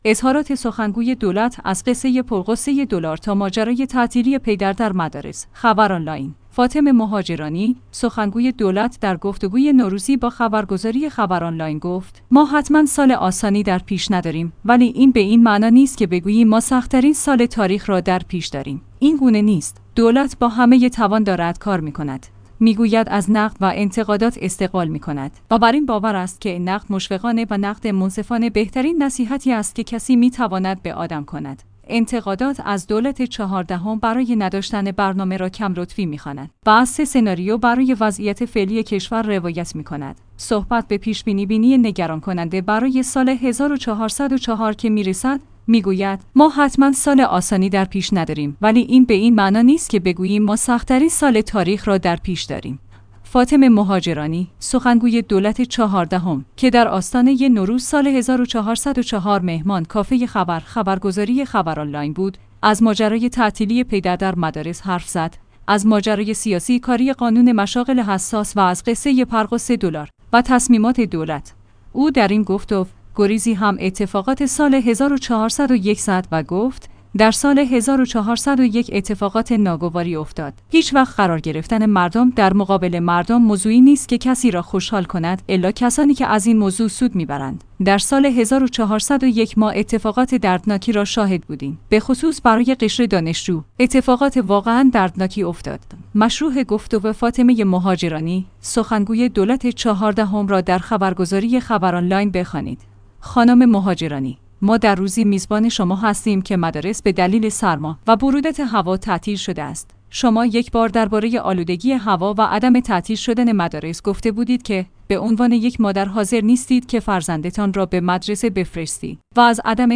خبرآنلاین/ فاطمه مهاجرانی، سخنگوی دولت در گفتگوی نوروزی با خبرگزاری خبرآنلاین گفت: ما حتما سال آسانی در پیش نداریم ولی این به این معنا نیست که بگوییم ما سخت‌ترین سال تاریخ را در پیش داریم.